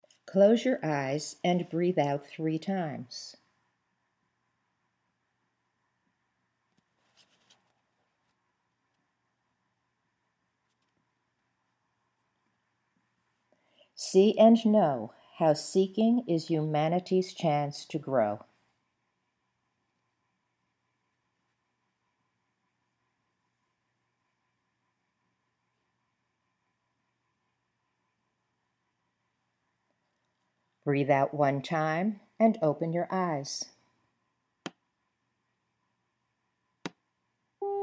There are ten seconds of silence on the tape for your breathing, followed by the Imagery exercises.
You’ll notice silences on the audio after the breathing instructions, typically ten seconds for breathe out three times and fewer seconds for fewer breaths.